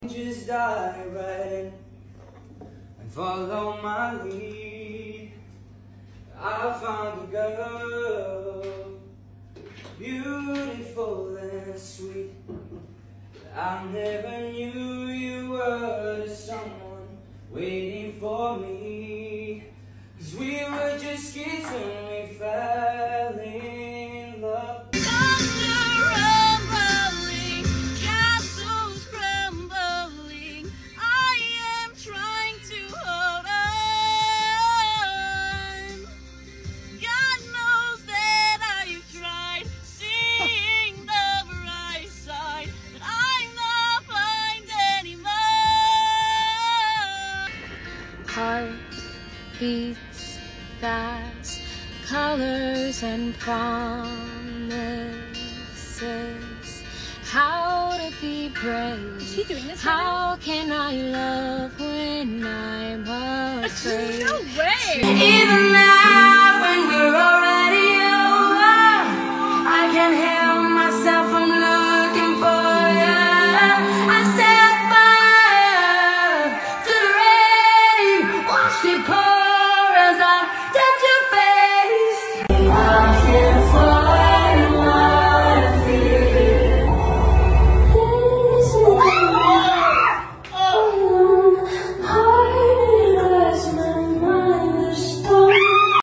Ranking The Best Singing Moments sound effects free download